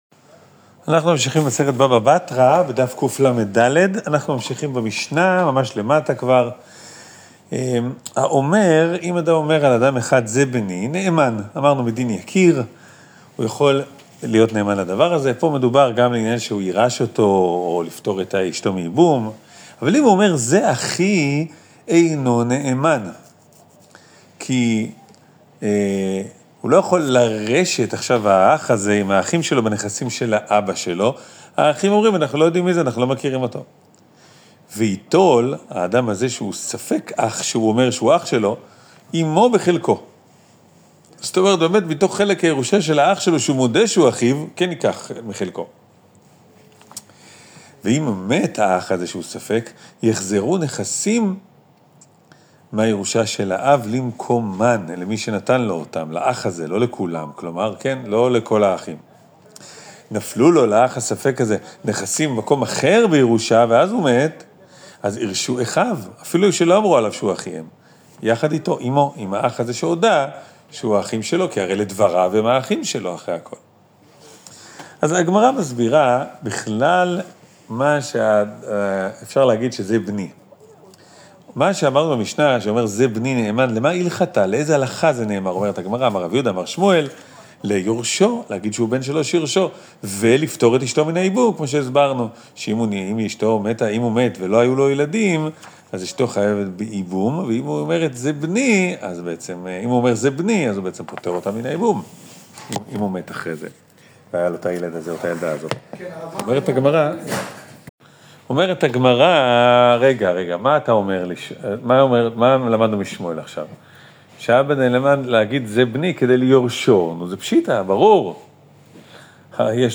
מגיד השיעור